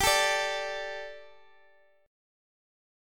D5/G chord